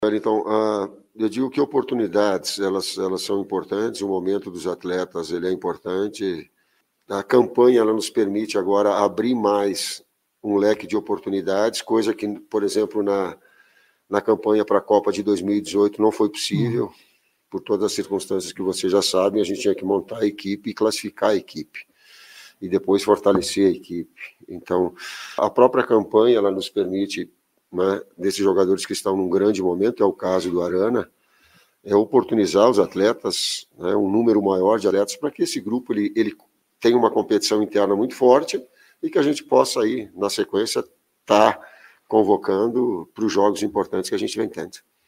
O técnico Tite, na entrevista coletiva nesta quarta-feira (06/10), em Bogotá na Colômbia, não só revelou o time como falou em dar oportunidade ao lateral Arana e observar mais os atletas de meio e ataque.